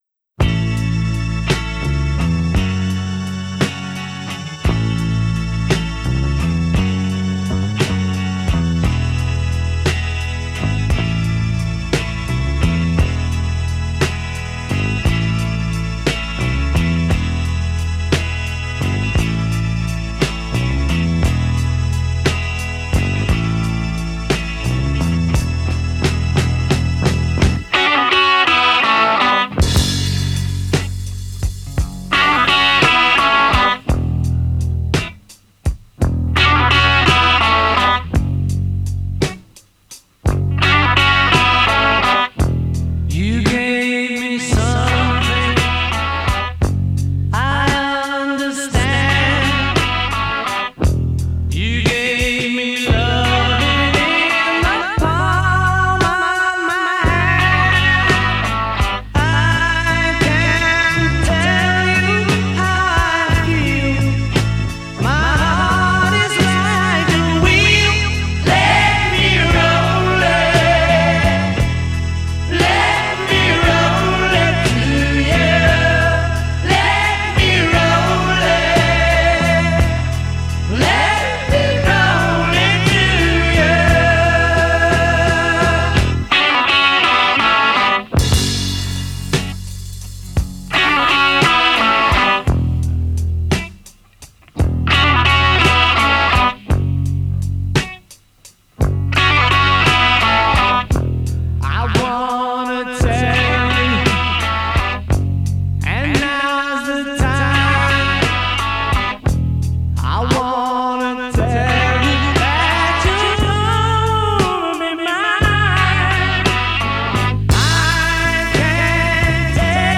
What a mellow song!